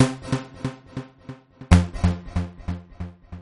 Descarga de Sonidos mp3 Gratis: melodia dj.